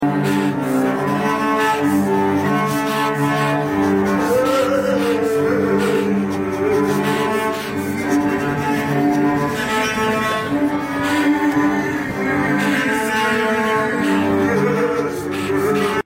Breath, 1,2,3; Buckingham Palace; London sound effects free download